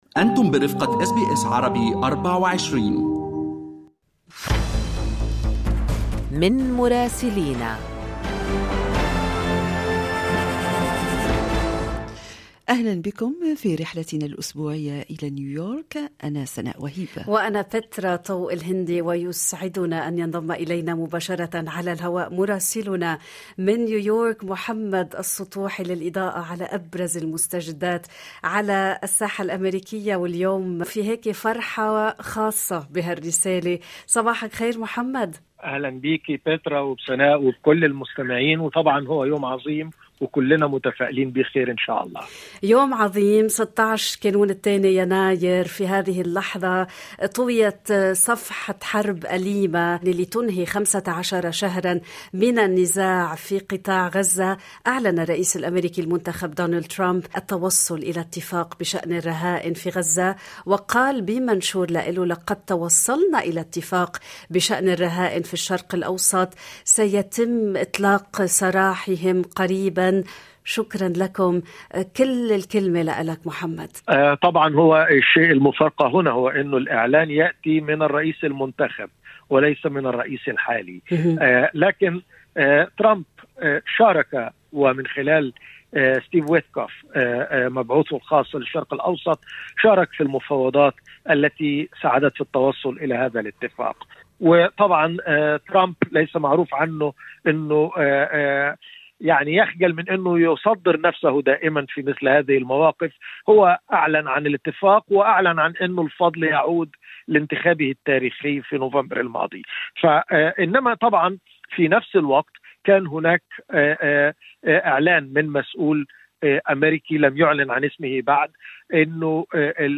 أهم أخبار الدول العربية مع مراسلينا من لبنان ومصر والأراضي الفلسطينية والعراق والولايات المتحدة.